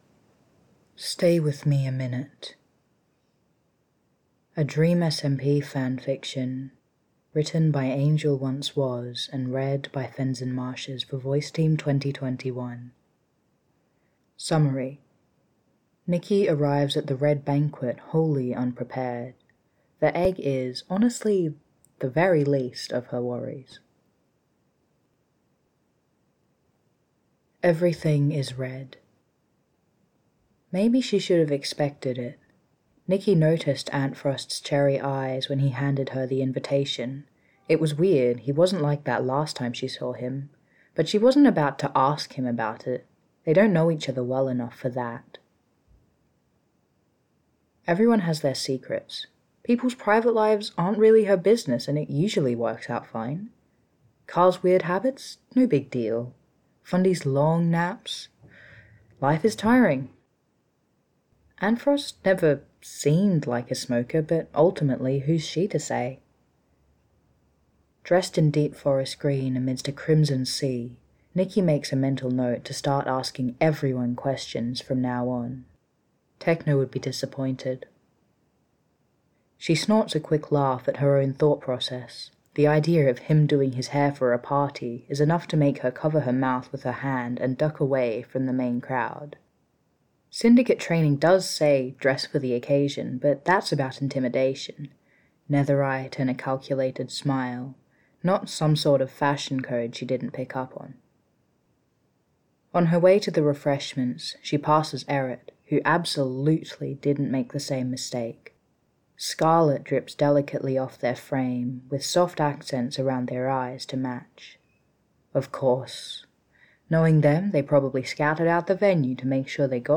without music: